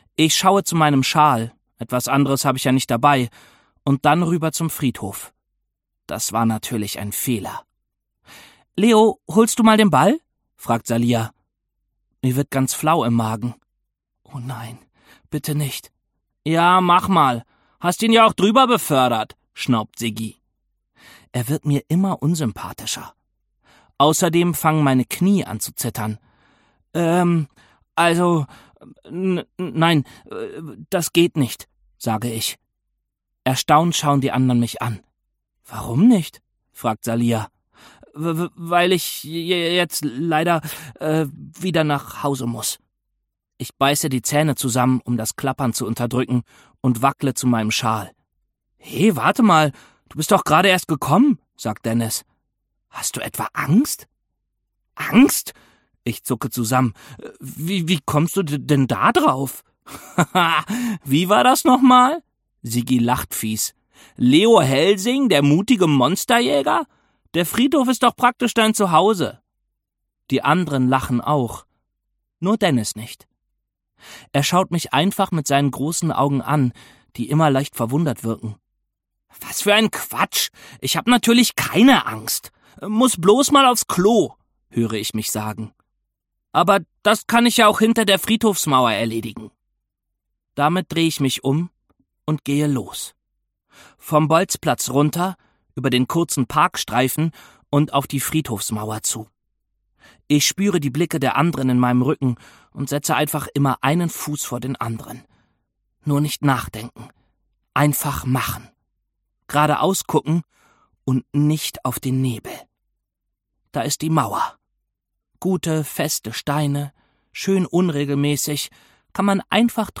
Ungekürzte Lesung cbj audio